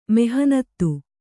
♪ mehanattu